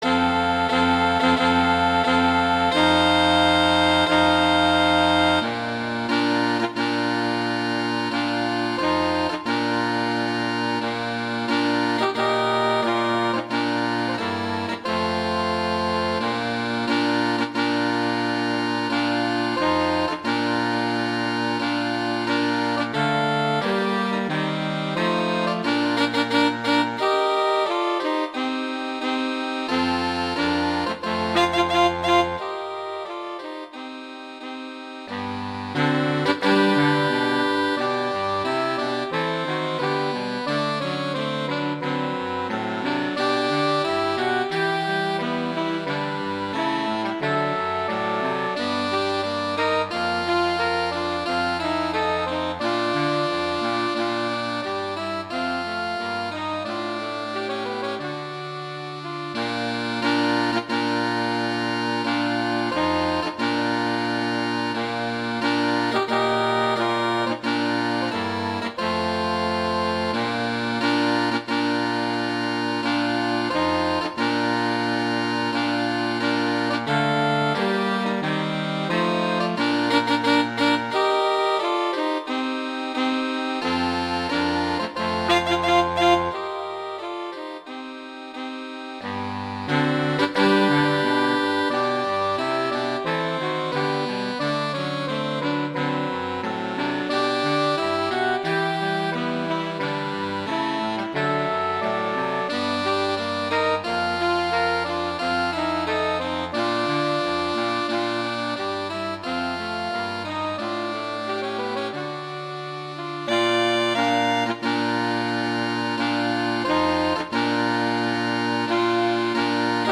Instrumentation: saxophone quartet
arrangements for saxophone quartet
wedding, traditional, classical, festival, love, french
baritone saxophone: